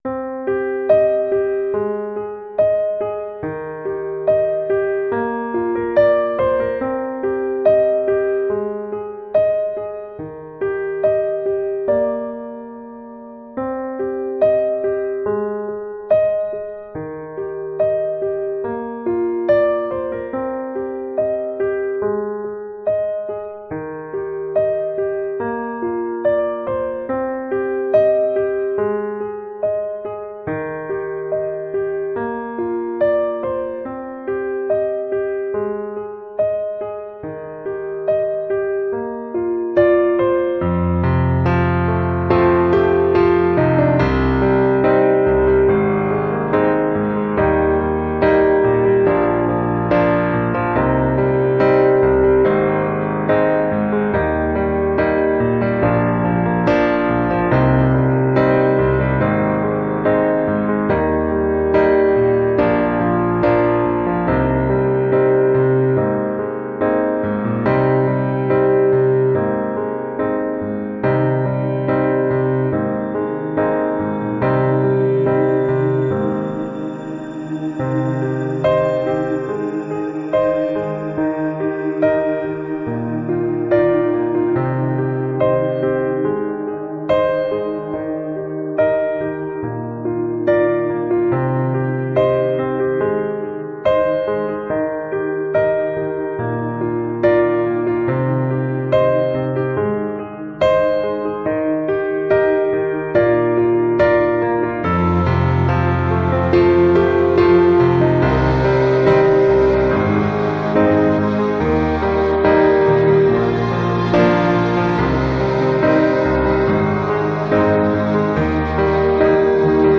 Instrumental:
• Genre: Atmospheric Indie-Folk
• Mood: Melancholic, restrained, cinematic